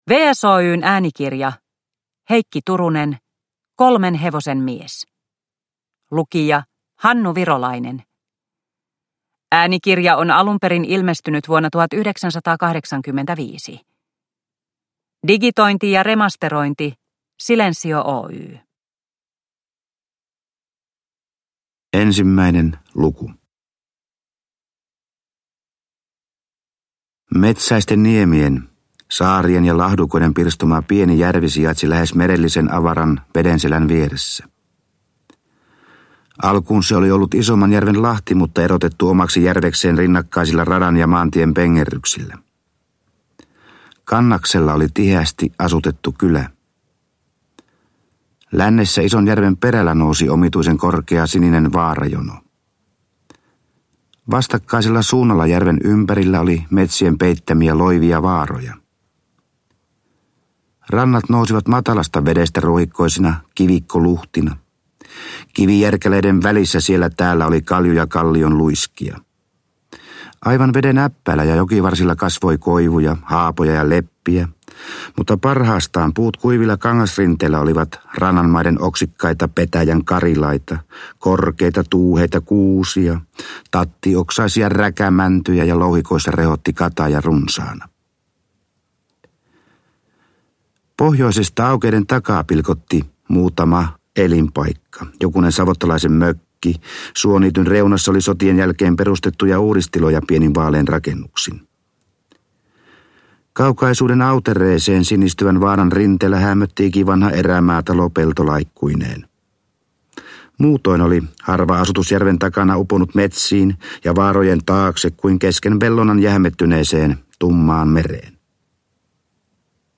Äänikirja on nauhoitettu 1985 ja digitoitu 2019.